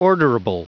Prononciation du mot orderable en anglais (fichier audio)
Prononciation du mot : orderable